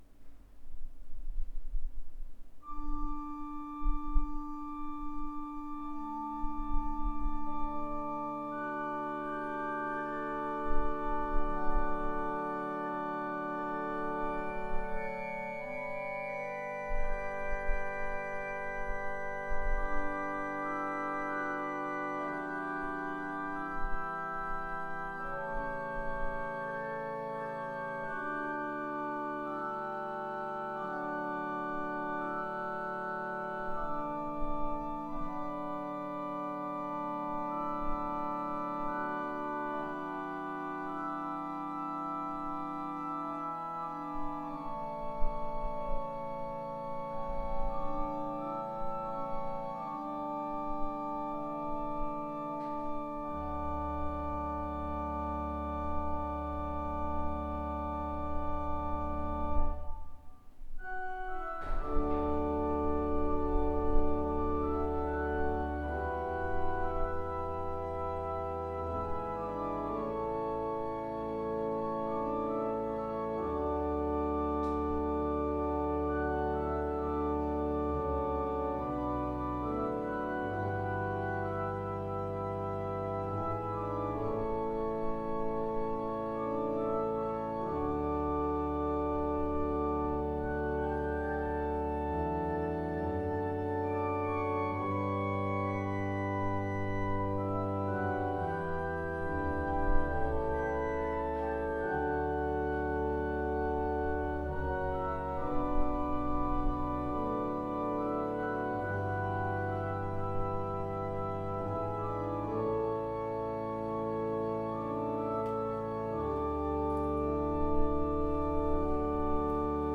Jeg brukte derfor opptakeren litt mer aktivt enn vanlig for å få litt mer av rommets lydbilde.
Det er fylldig og god bass i orgelet og med to 16' stemmer å gå på er det også lett skape gode kombinasjoner med ønsket fyllde i bunnen.
Rommet har helt grei gjenklang og instrumentet føles godt balansert mot det.
Blott en dag Jeg prøvde her ut et utvalg av kjente salmer, min egen fanfare og en improvisasjon hvor jeg prøver ut stemmer og klanger. Det er lett støy på enkelte av opptakene, men det var åpen kirke når jeg var der.
Hammerfest kirke   ZOOM H4n PRO 18.06.2023